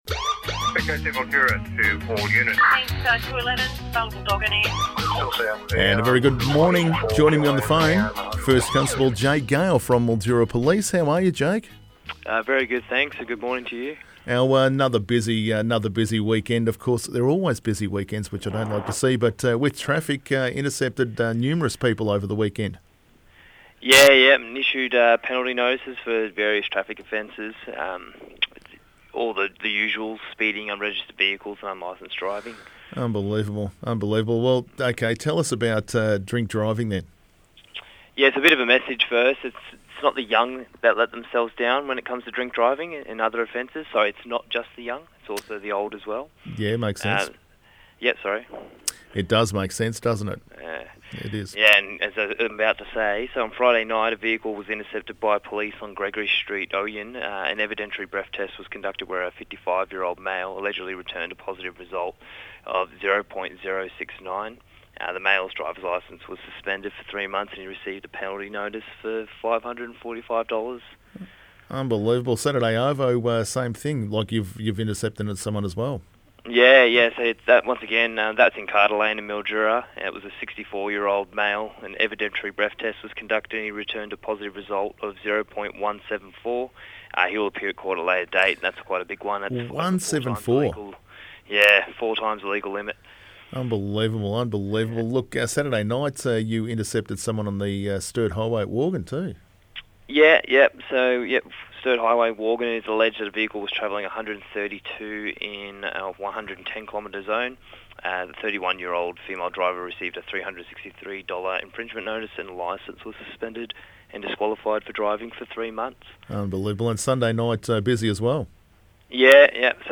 This morning conversation covered topics including drink driving, speeding, arrests, police patrols around entertainment and event areas and Crime Stoppers.